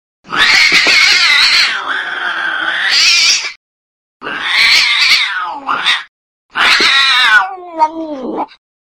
zvuk-orushhego-kota_004
zvuk-orushhego-kota_004.mp3